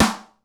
DrSnare3.wav